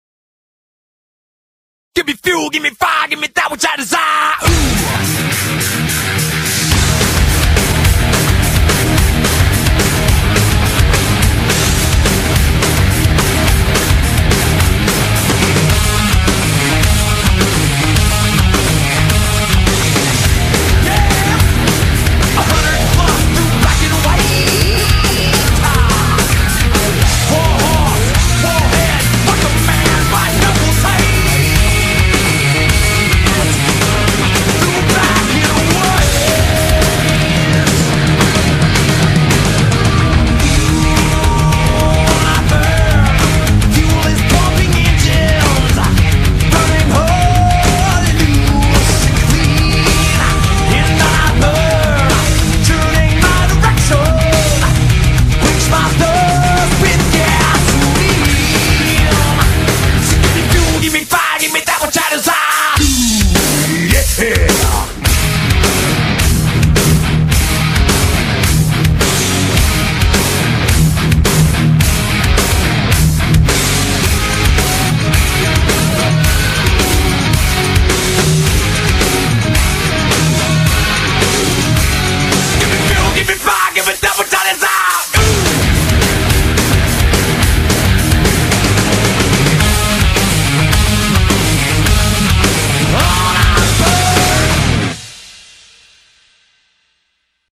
BPM106-219
MP3 QualityMusic Cut